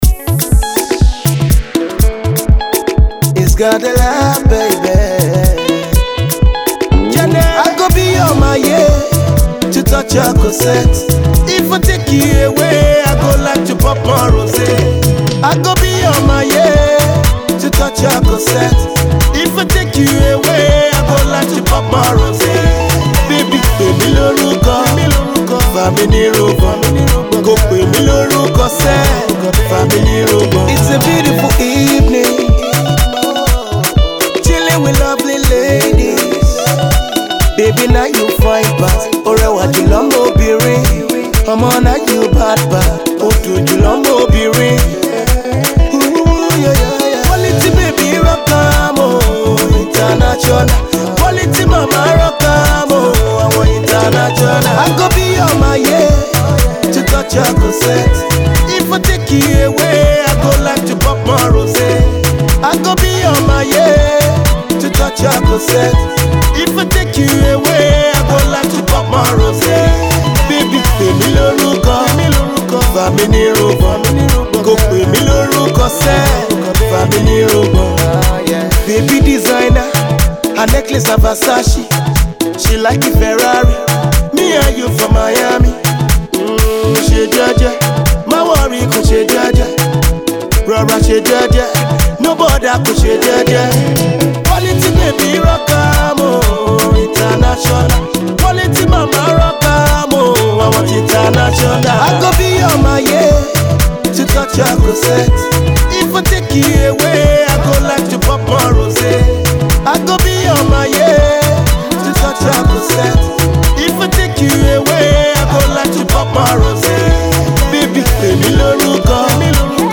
Pop
melodious